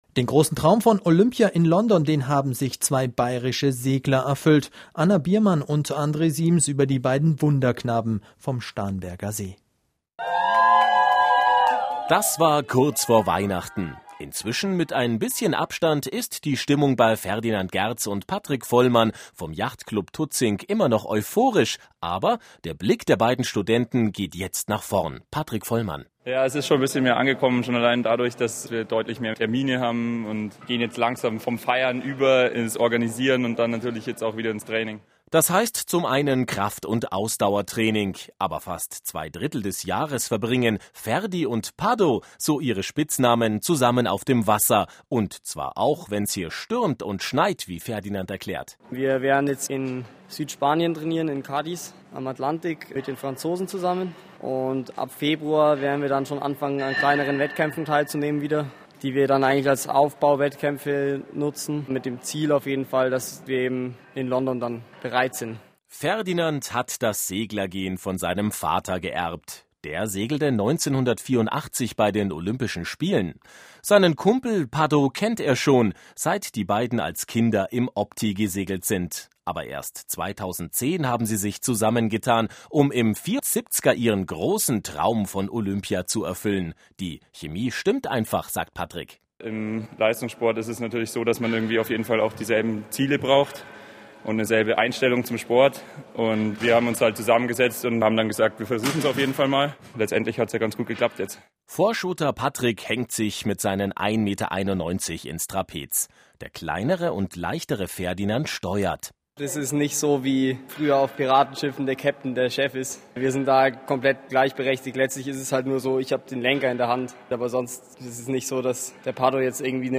Radiobeitrag auf B5